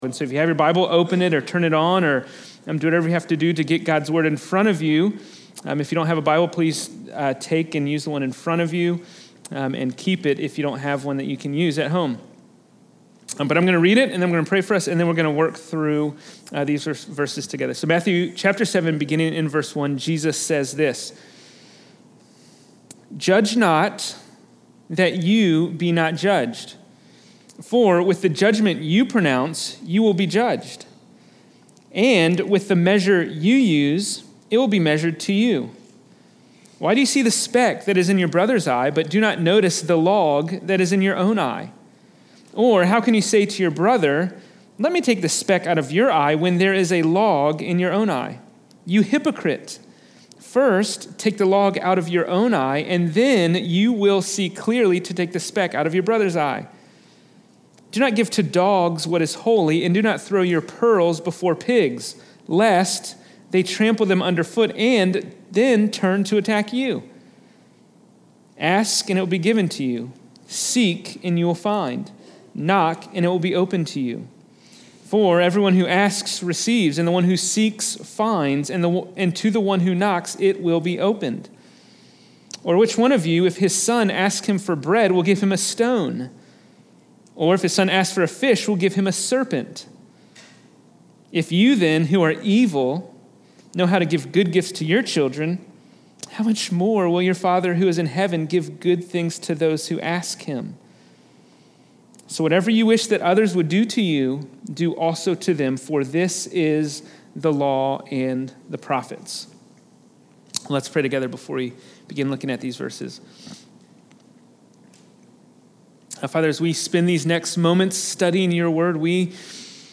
Fox Hill Road Baptist Church Sermons